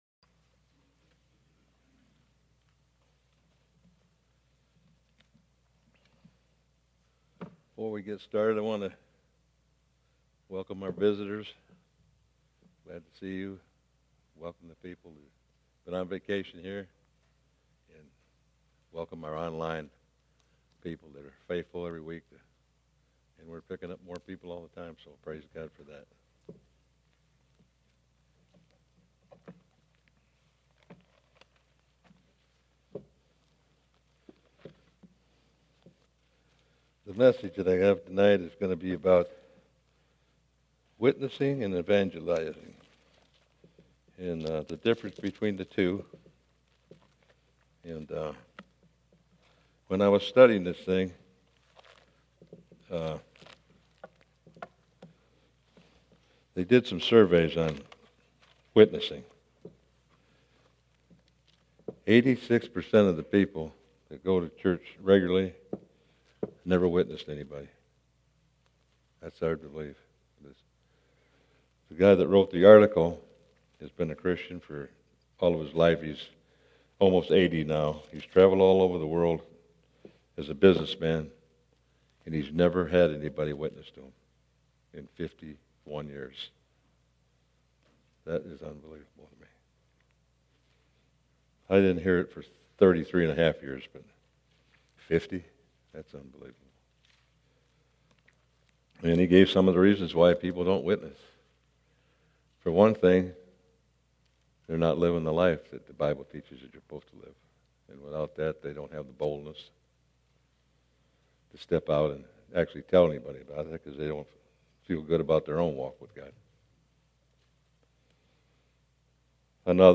Altar Call